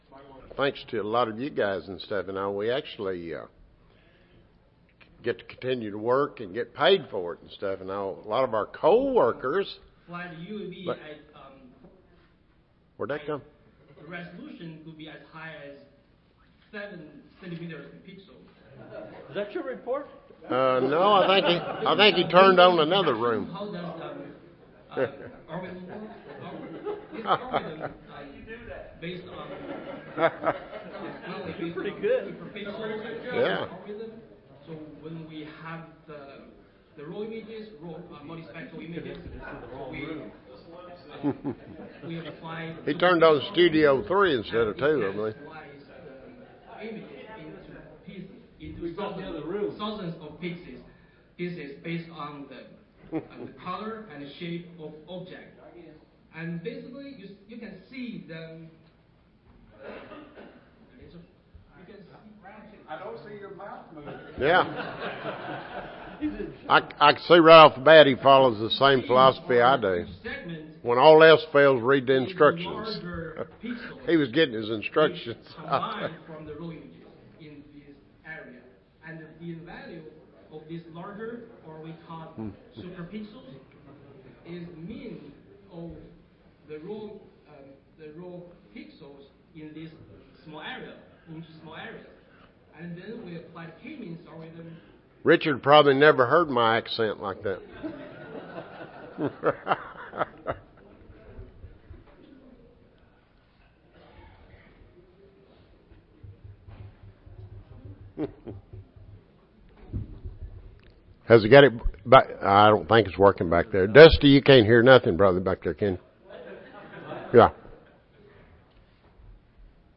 AMS Cotton and Tobacco Program Audio File Recorded Presentation Summary of US Cotton crop quality for 2018 season compared to previous crops for numerous quality factors.